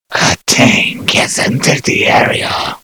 mvm_tank_alerts01.mp3